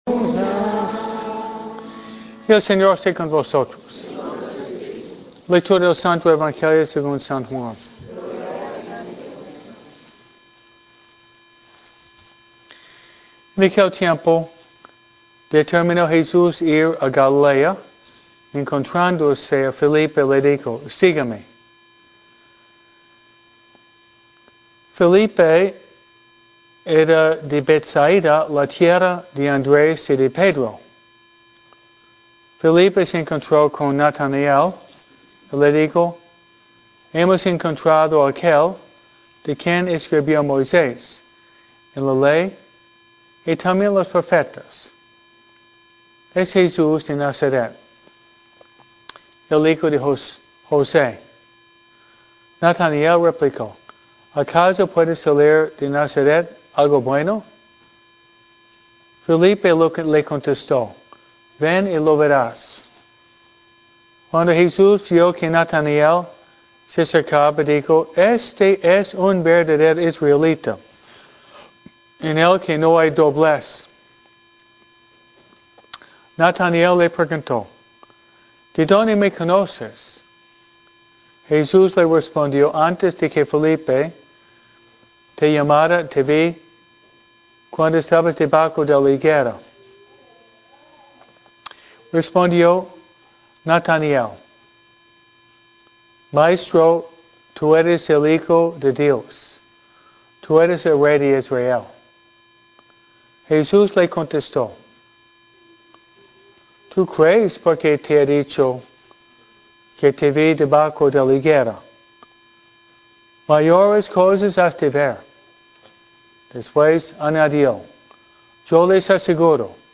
MISA – EL AMOR AUTENTICO